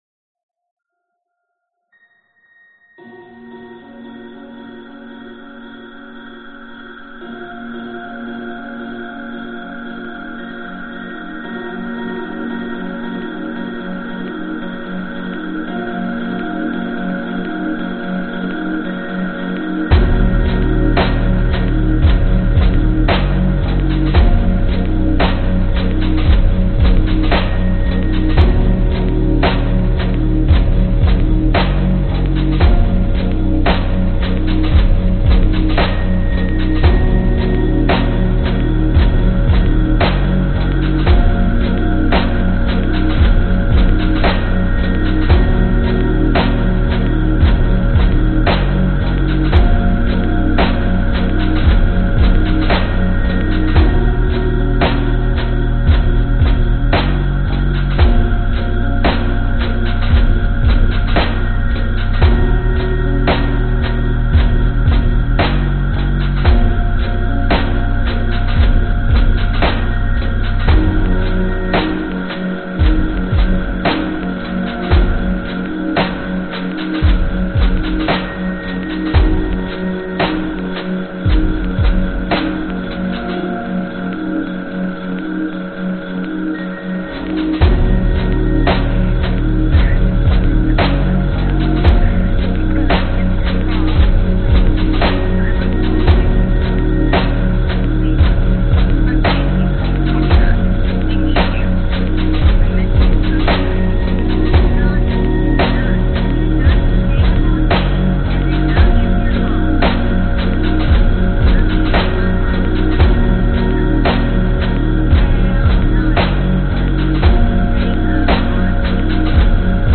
标签： instrumental electronic ambient downtempo
声道立体声